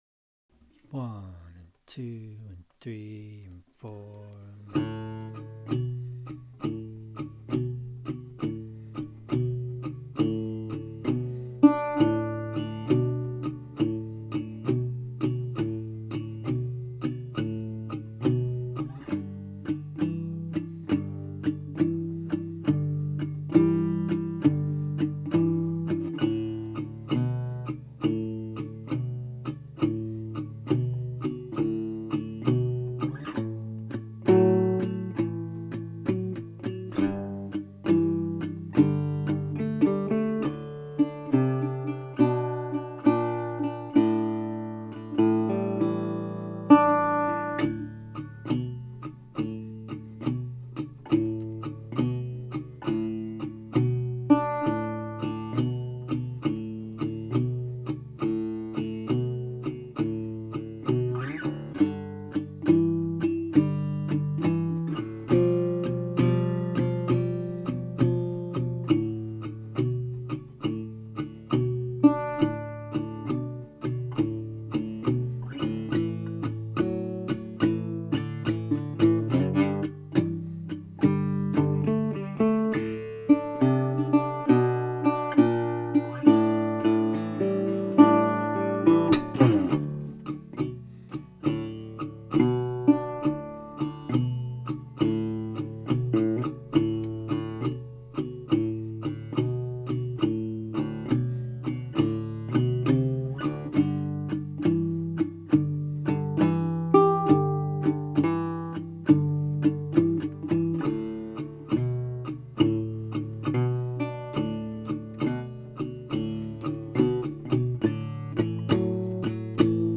12 bar blues backing track in D.